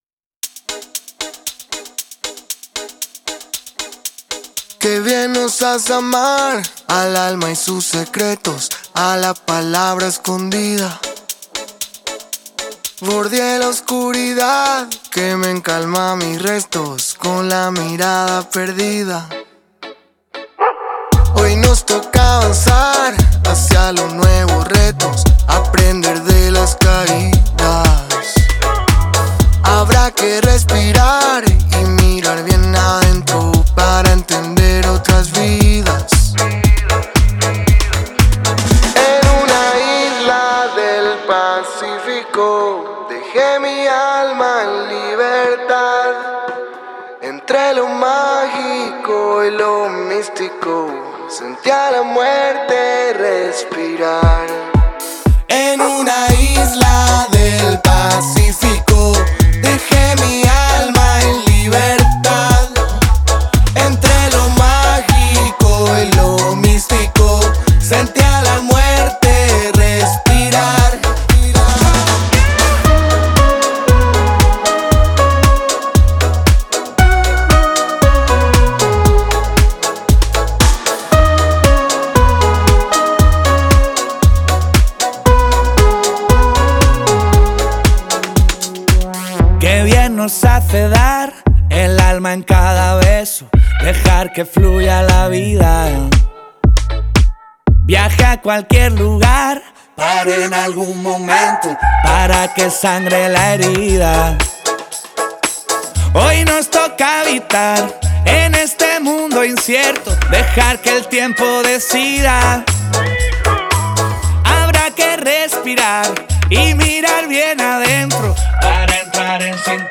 artista español
creando una canción alegre